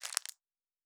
Plastic Foley 14.wav